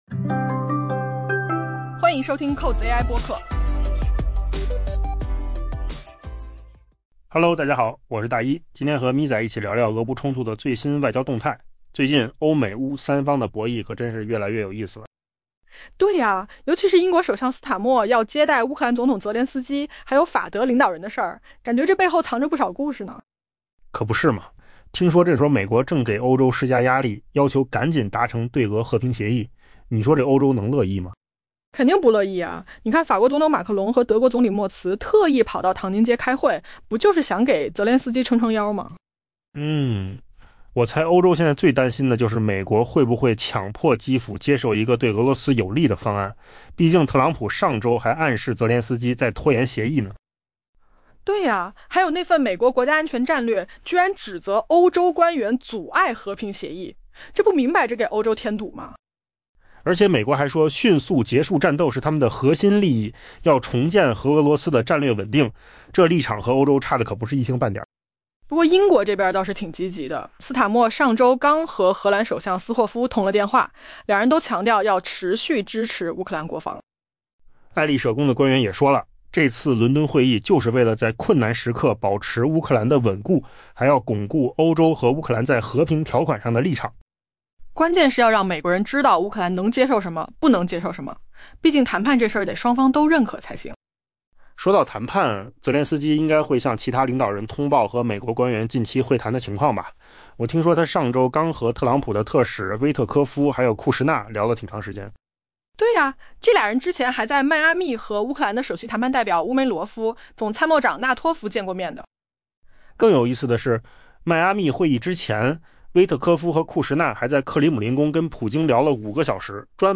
AI 播客：换个方式听新闻 下载 mp3 音频由扣子空间生成 英国首相斯塔默准备接待乌克兰总统泽连斯基以及法德领导人，此时美国正施加越来越大的压力，要求达成对俄和平协议。